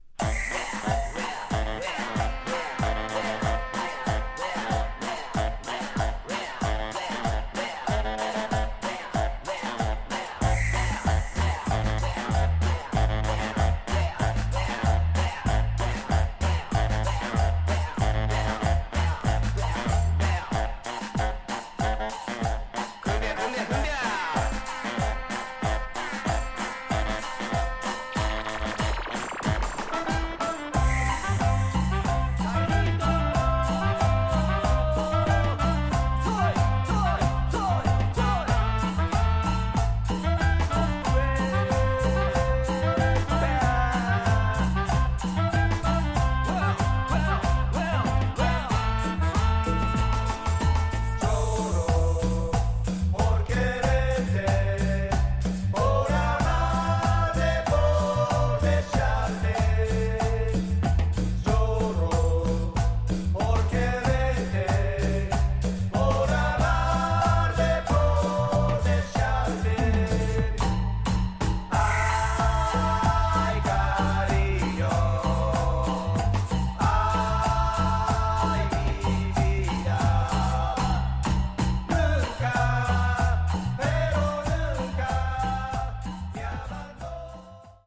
Tags: Japan , Cumbia , Remix